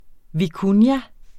Udtale [ viˈkunja ]